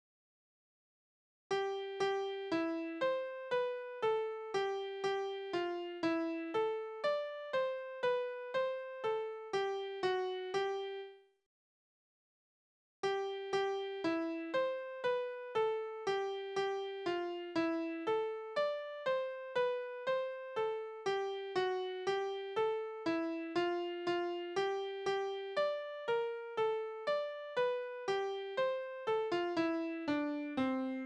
Geistliche Lieder:
Tonart: C-Dur
Taktart: 4/4
Tonumfang: große None
Besetzung: vokal